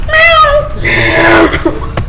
Meow!!! Sound Button - Free Download & Play